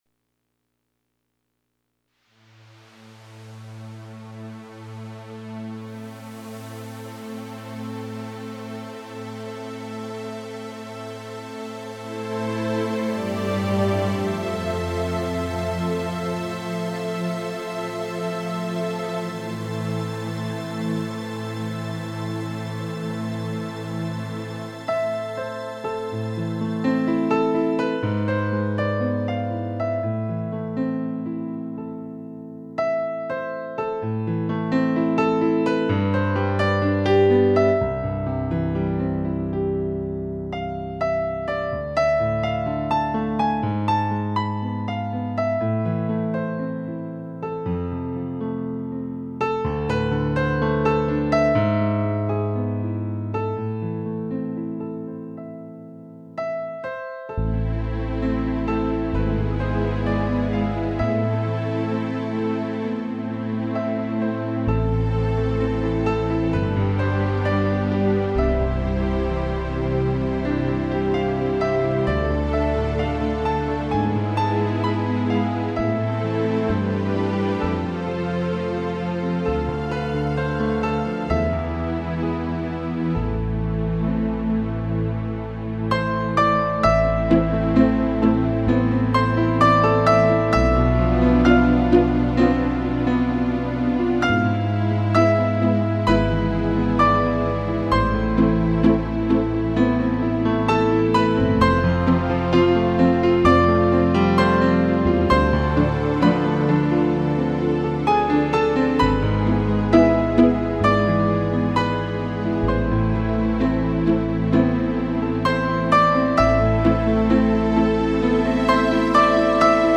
I composed the music and perform on the piano for the CD.
background accompaniment…Unlike some piano presentations,